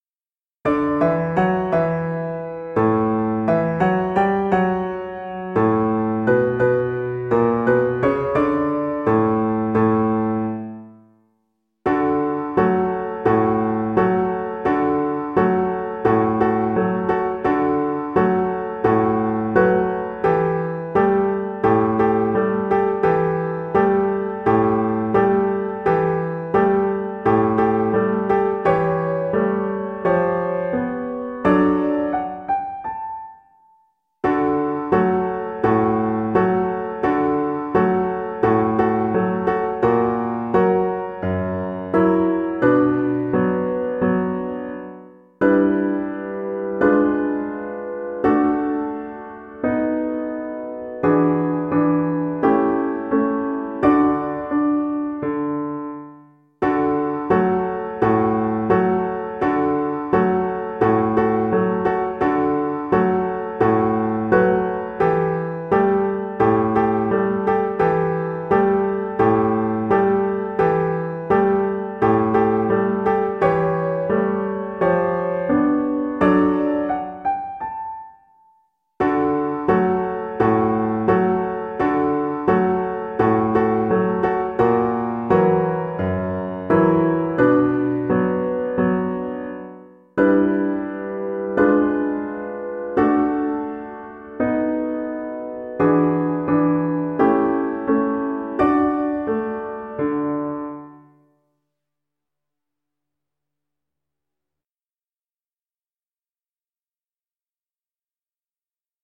Violin
4/4 (View more 4/4 Music)
Arrangement for Violin and Piano
D major (Sounding Pitch) (View more D major Music for Violin )
Jazz (View more Jazz Violin Music)
Ragtime Music for Violin